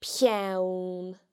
The broad nn can also be heard in peann (a pen):